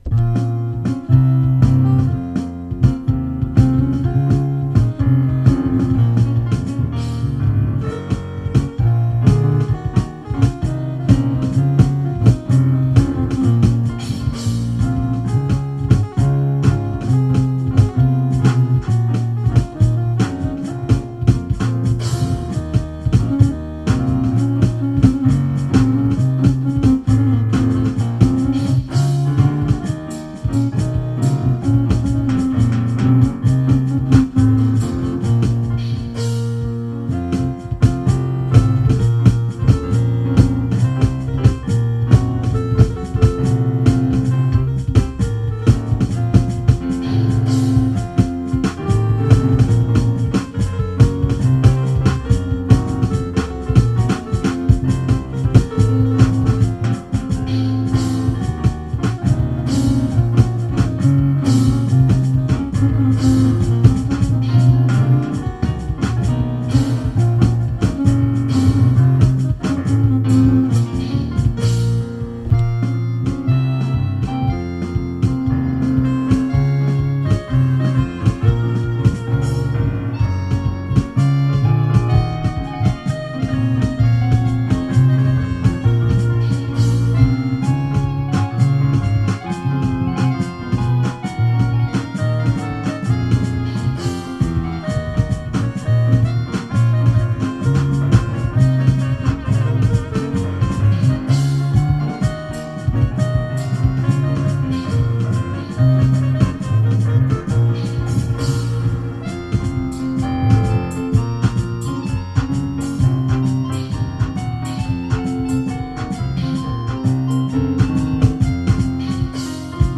(לפסנתר, בס, תופים, סקסופון וחצוצרה).
רק שהתופים קצת צרמו ולא השתלבו יפה עם כל שאר הכלים...
אבל קצת לא היה בקצב בקטעים מסוימים